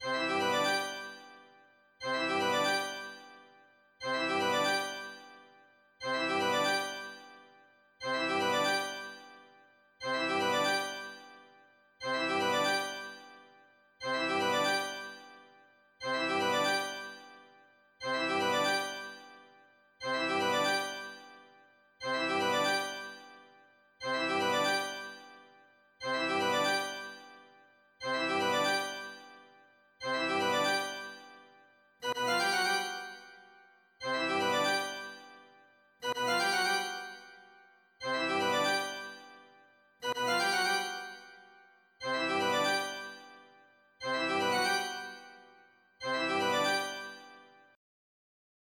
Cinematic Hopeful 00:47